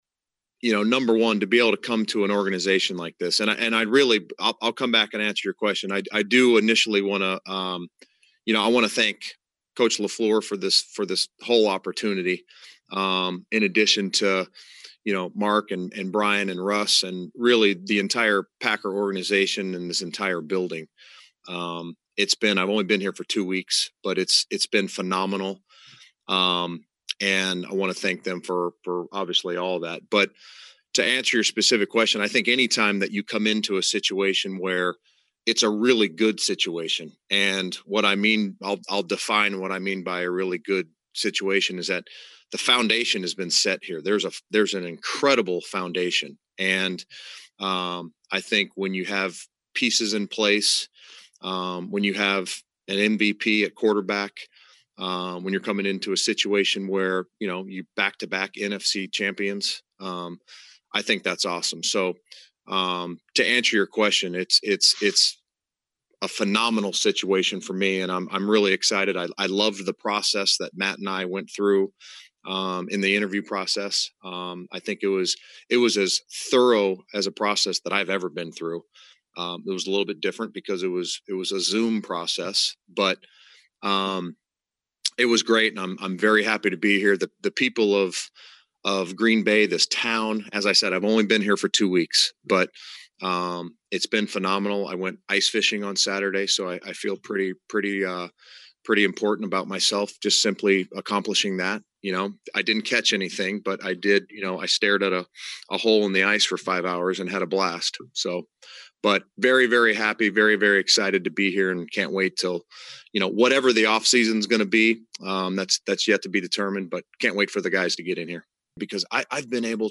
Barry fielded only a handful of questions during his media session as he went into great detail on each, beginning with his reunion with LaFleur, his acclimation to Green Bay and his hopes for the Packer defense.